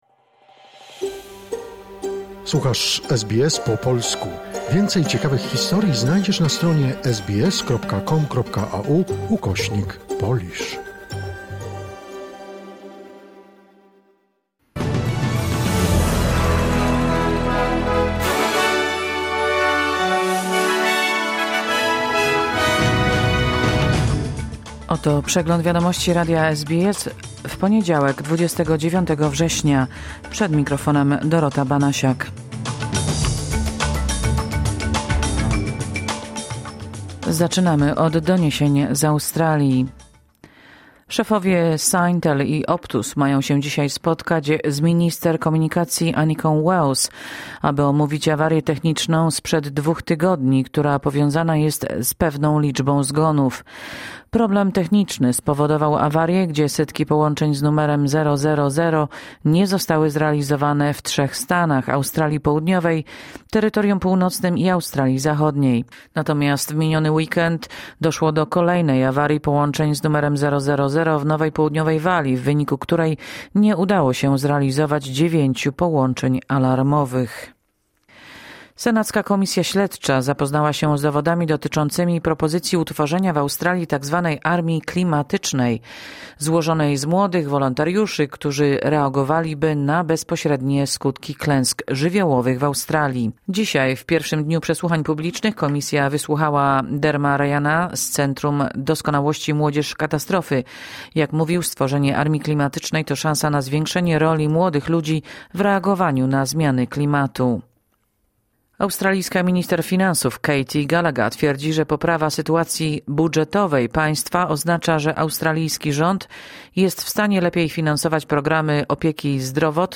Wiadomości 29 września SBS News Flash